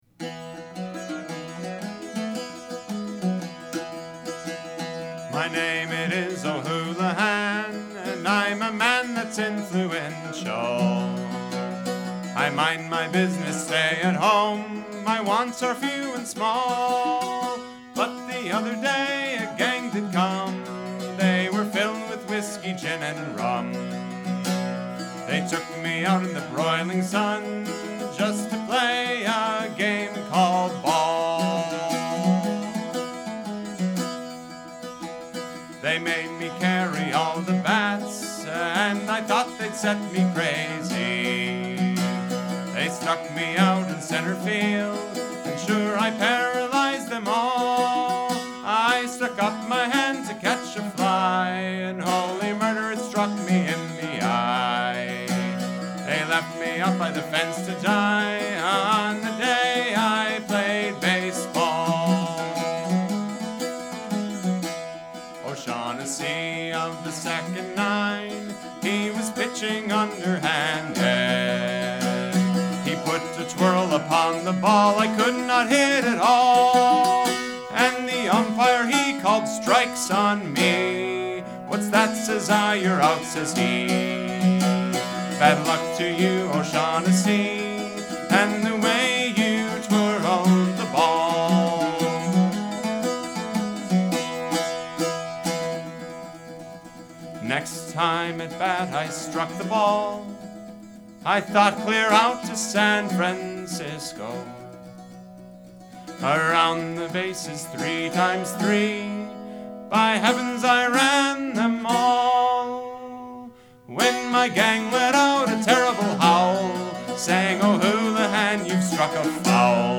The Day That I Played Baseball Ballad Printed songbook, p. 66